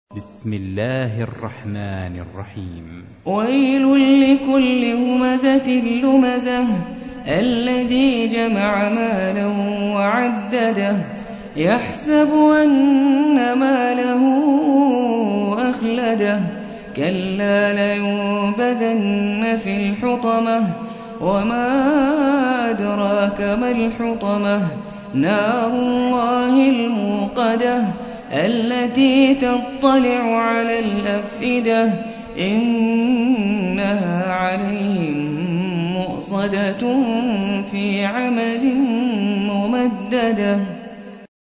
104. سورة الهمزة / القارئ